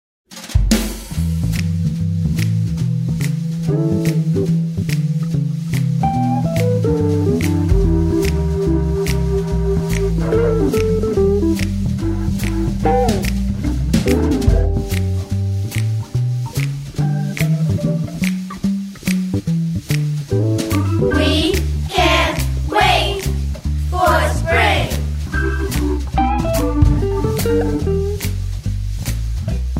Instrumental Tracks.
▪ The full instrumental track with vocal melody